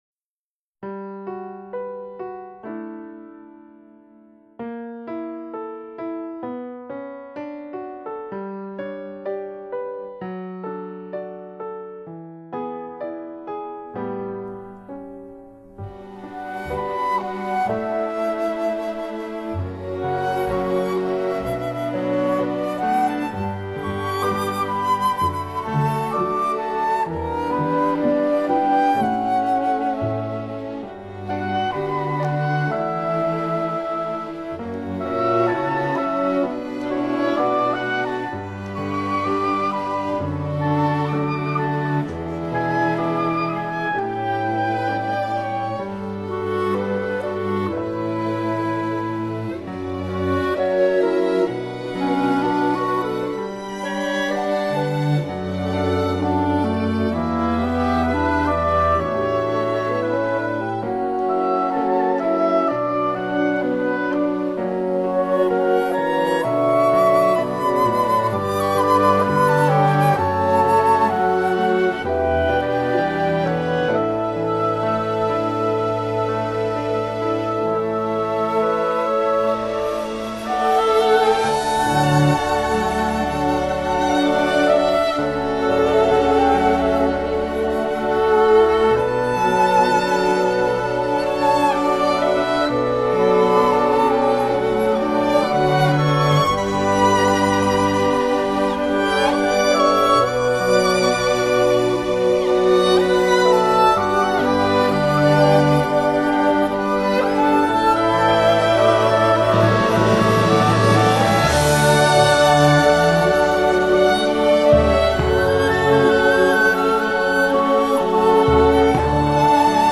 於是，「墾丁」音樂洋溢著Bossa Nova、Reggea風，因為不論老少，這裡只有熱帶與熱情。